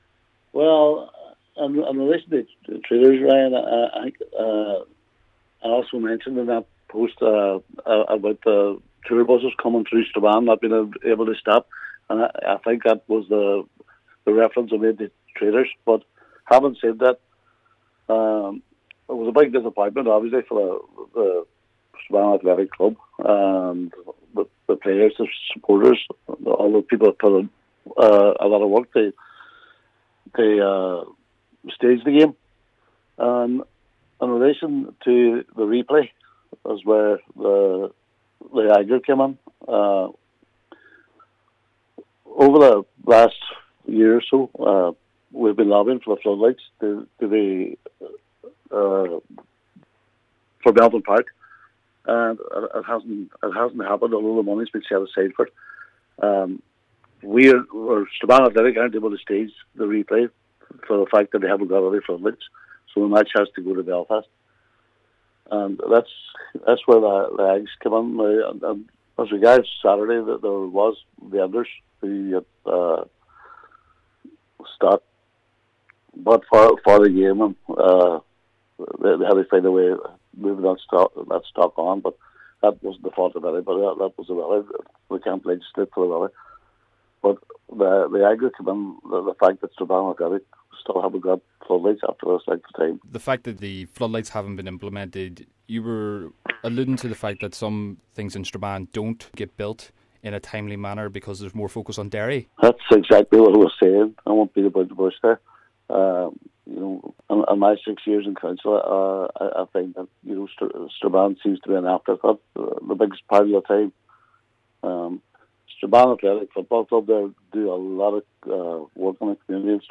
Cllr Raymond Barr pointed out some of the differences he can see: